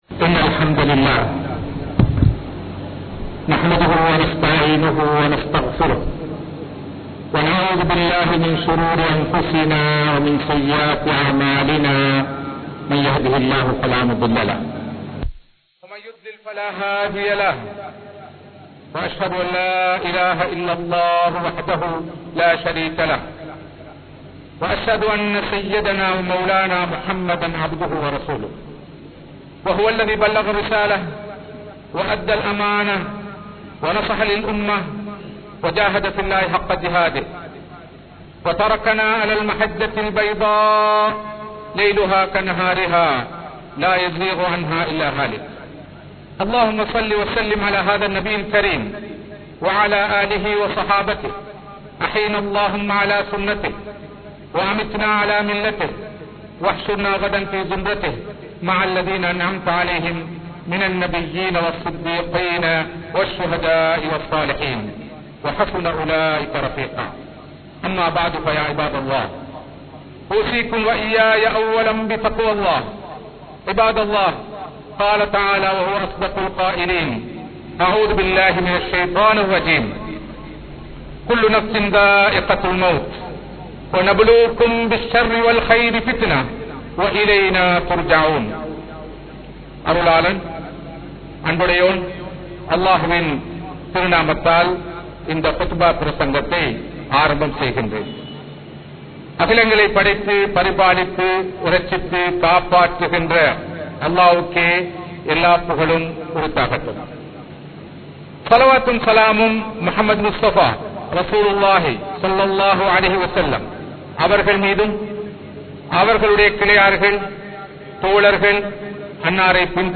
Iyatkai Anarththam Sollum Seithi Enna? (இயற்கை அனர்த்தம் சொல்லும் செய்தி என்ன?) | Audio Bayans | All Ceylon Muslim Youth Community | Addalaichenai